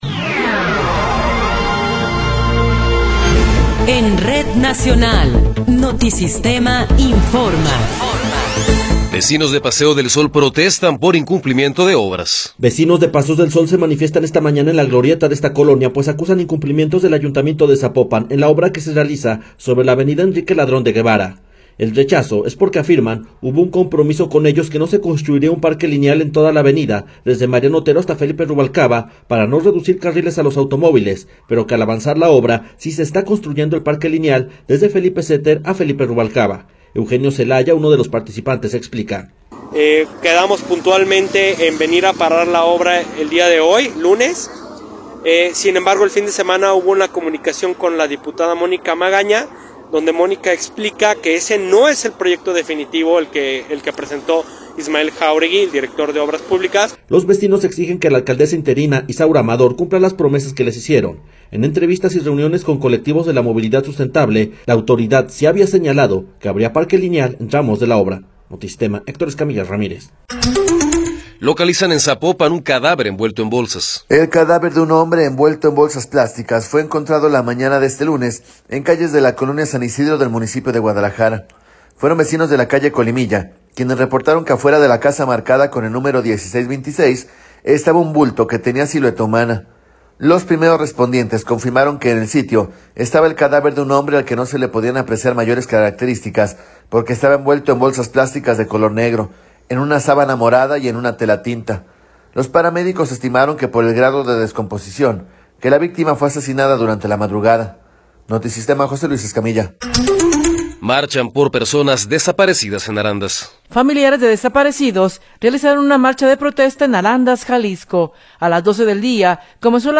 Noticiero 11 hrs. – 19 de Febrero de 2024
Resumen informativo Notisistema, la mejor y más completa información cada hora en la hora.